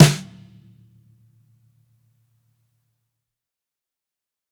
GADDISH_SNARE_LOUD.wav